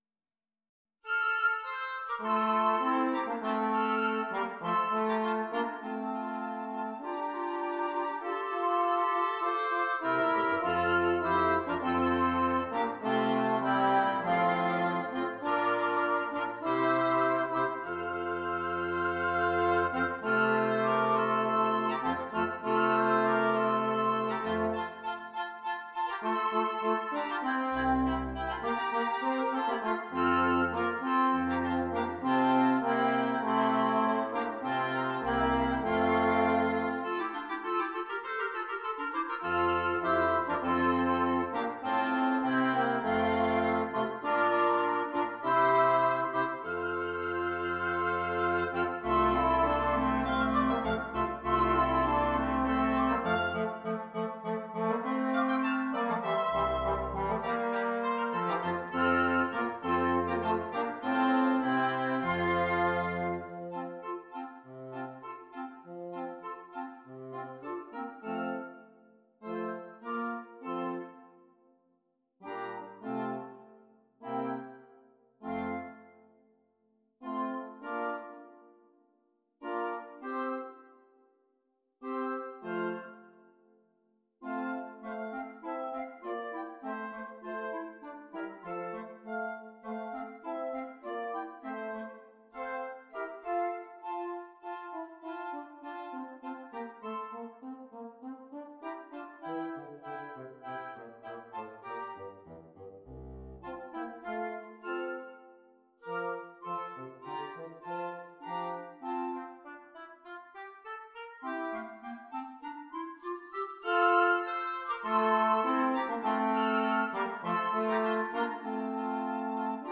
Woodwind Quintet
Traditional